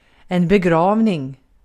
Uttal
Synonymer gravsättning jordfästning Uttal Okänd accent: IPA: /begˈravniŋ/ Ordet hittades på dessa språk: svenska Översättning 1. cenaze 2. defin Artikel: en .